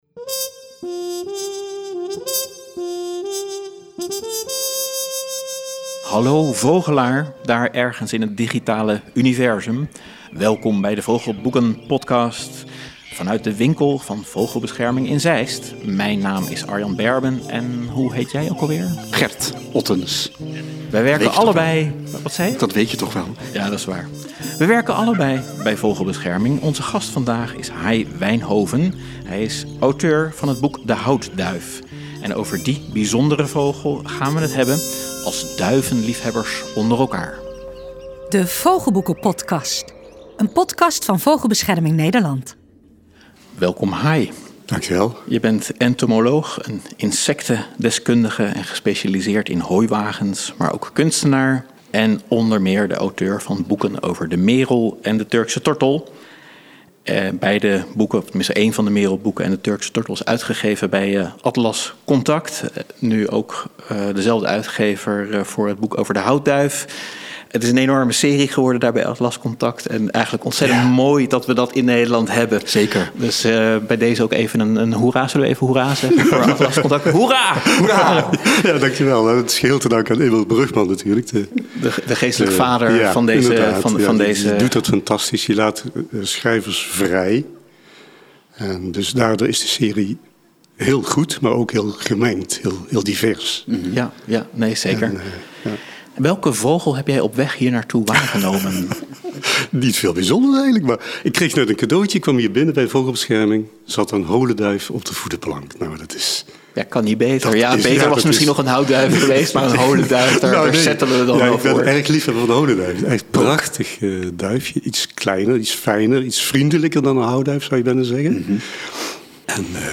Een vrolijk gesprek tussen boekenwurmen.